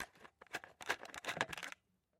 Звуки пульверизатора
Звук отвинчивания крышечки на распылителе